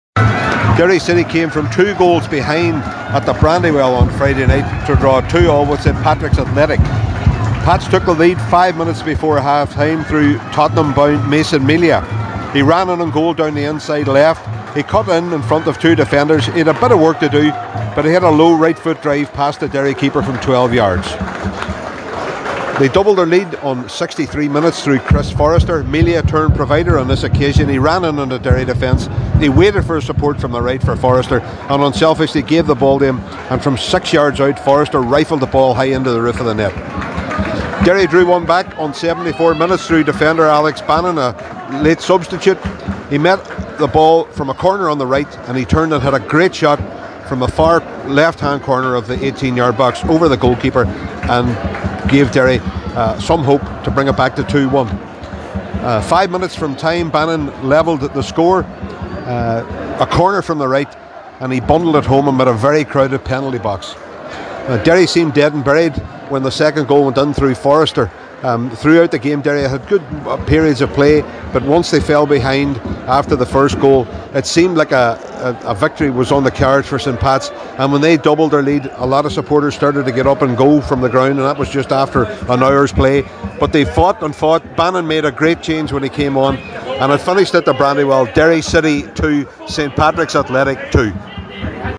Derry-report-v-St-Pat-s.mp3